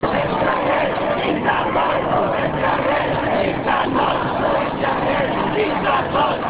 During the ceremony, he was just standing, and certainly had difficulties when the crowd was shouting and whistling.